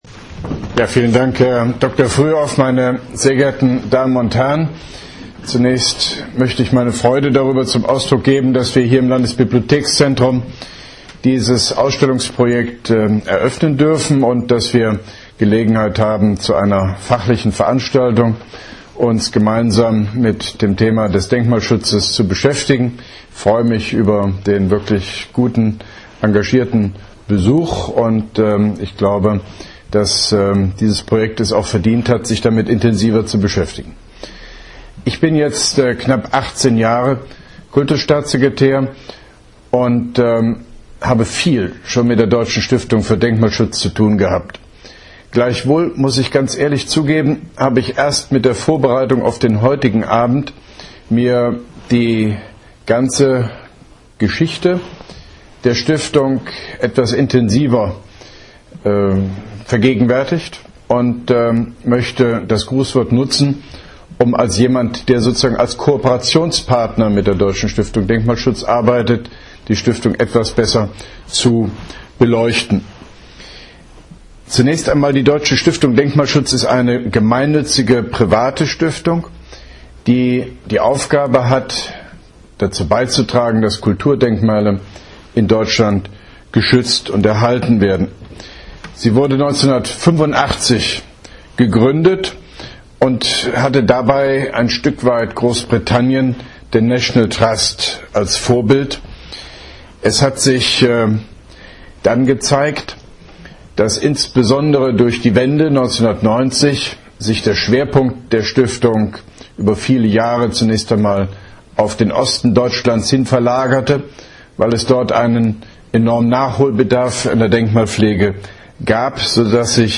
Grußwort Deutsche Stiftung Denkmalschutz